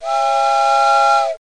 TrainPass.mp3